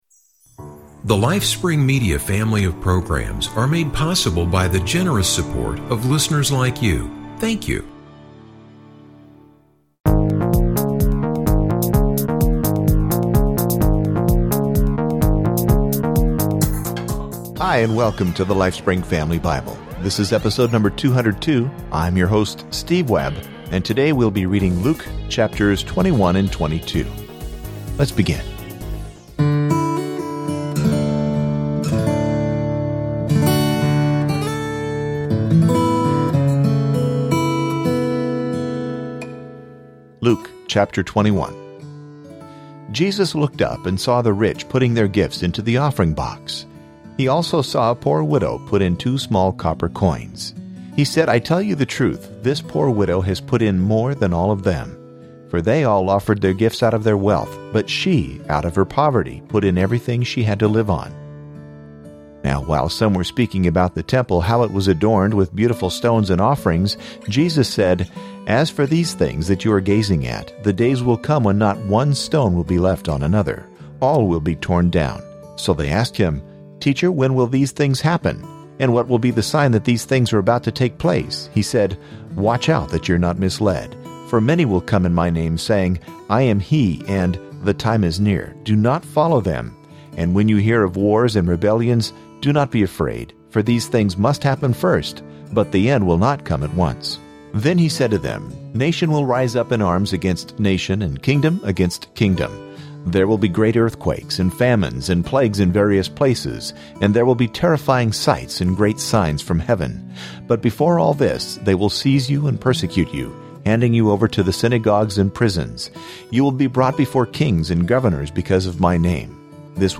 Each episode features a reading, followed by a short commentary.